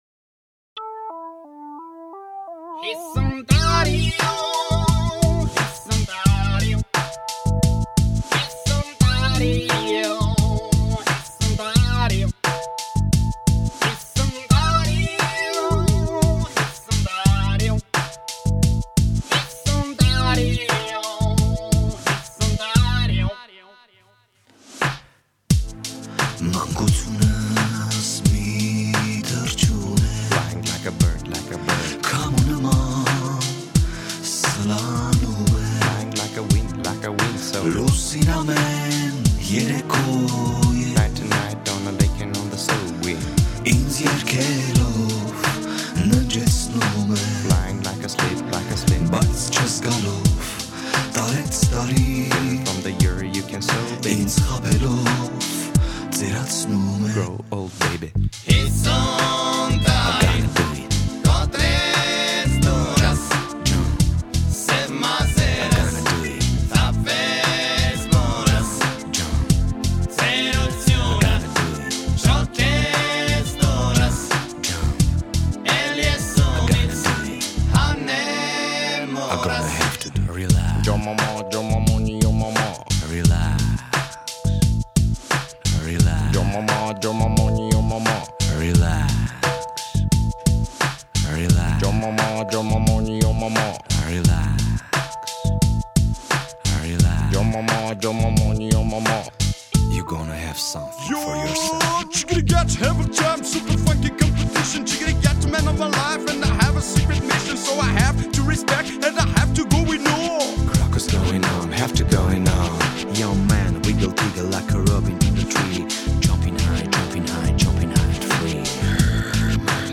rabiz music